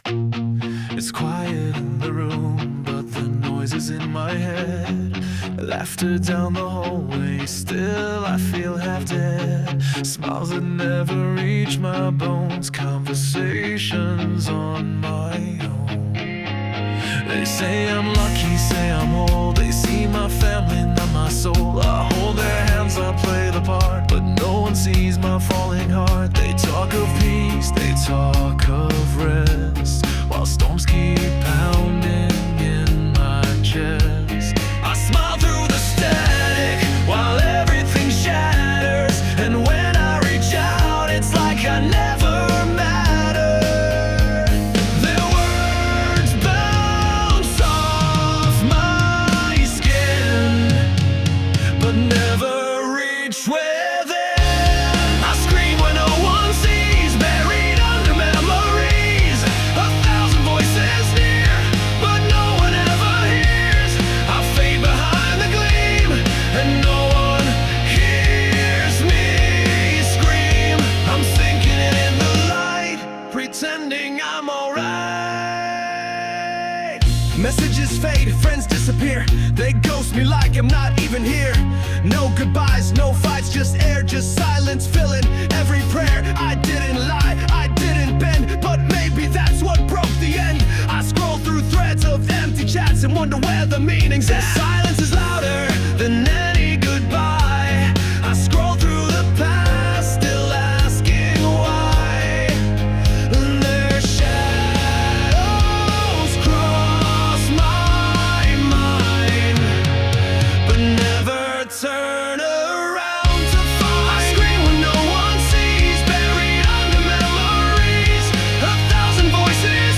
Rock Song (Untitled #1 – SeaArt AI, 2023)